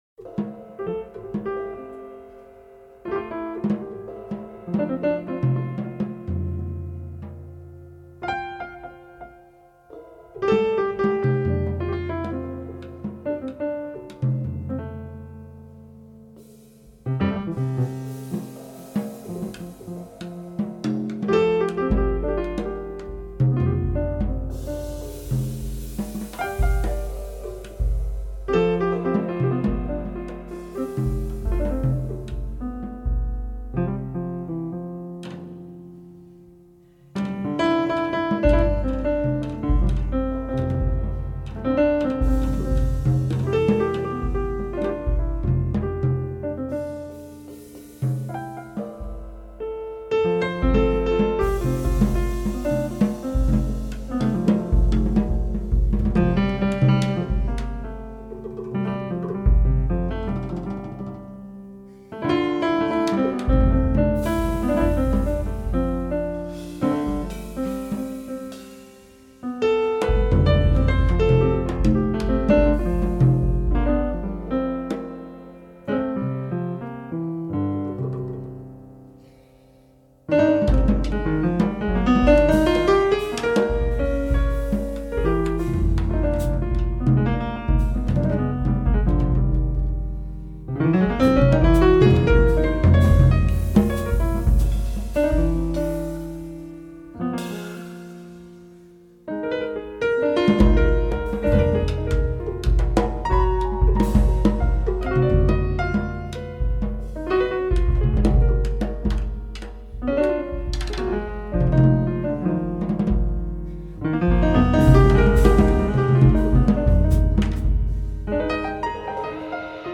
Duo
guitars
vibes